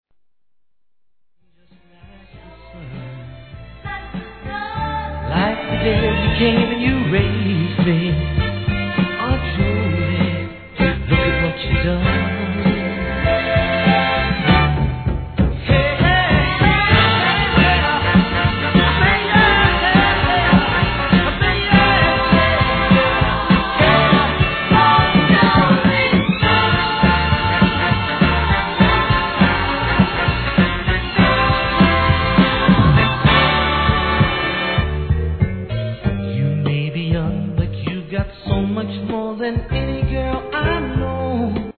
HIP HOP/R&B
大好評！！オトナなパーティーを彩るダンクラ、フリーソウルがギッシリ！！